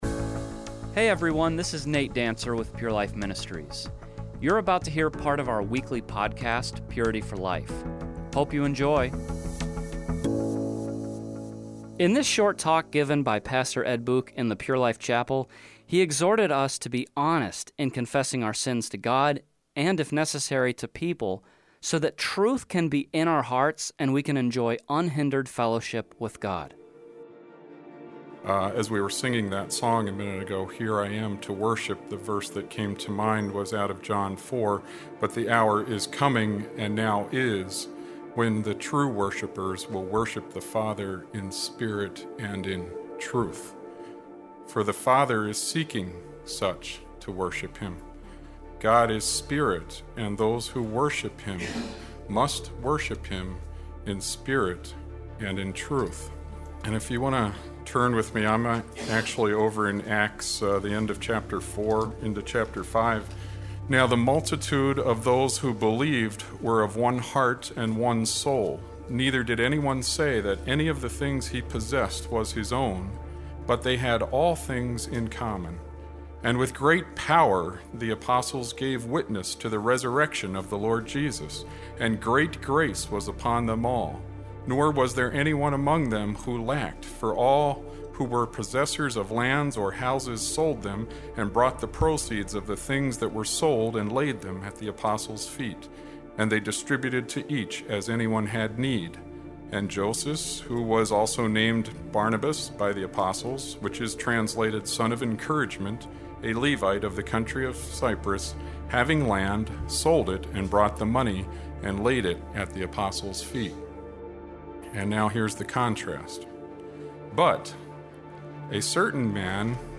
message
in Pure Life’s chapel